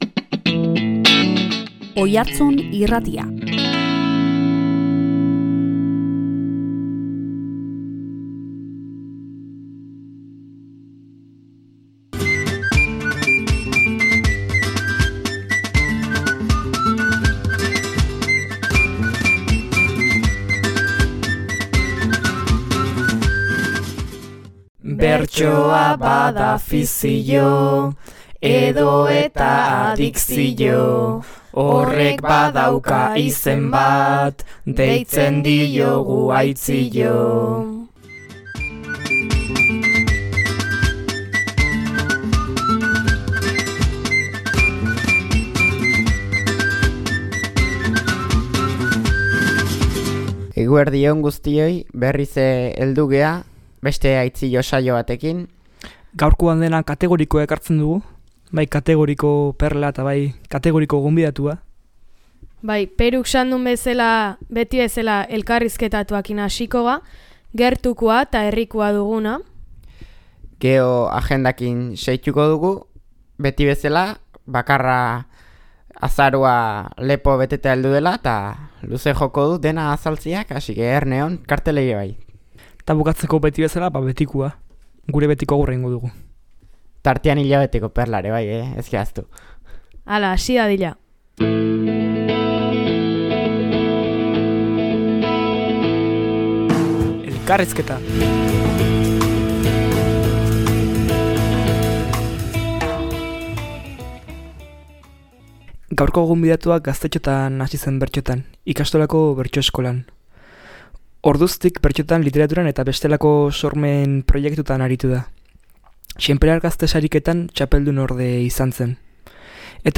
Hileko azken ostiraletan bertsoa dugu protagonista Oiartzun Irratian.
Azaroko agendaren berri eman eta amaitzeko, puntuka egin dute saioaren laburpena.